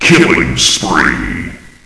flak_m/sounds/announcer/int/killingspree.ogg at 86e4571f7d968cc283817f5db8ed1df173ad3393
killingspree.ogg